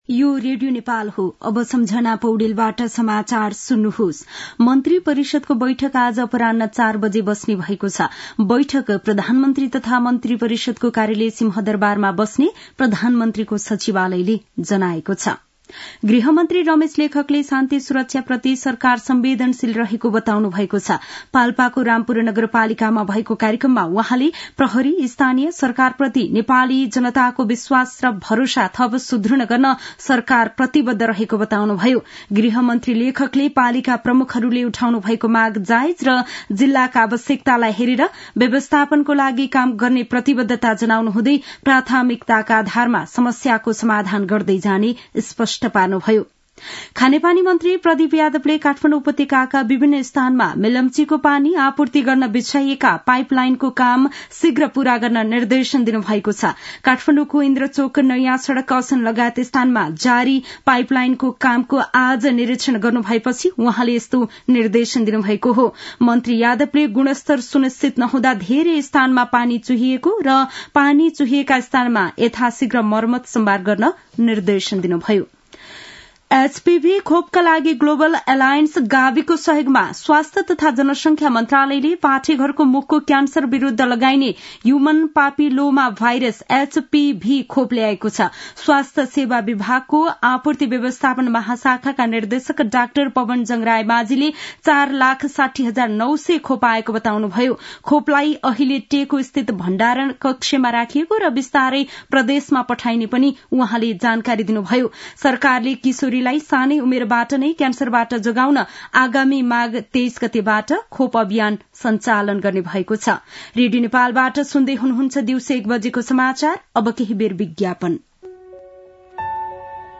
दिउँसो १ बजेको नेपाली समाचार : ५ पुष , २०८१
1-pm-nepali-news-1-14.mp3